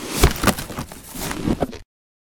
barrel.ogg